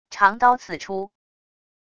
长刀刺出wav音频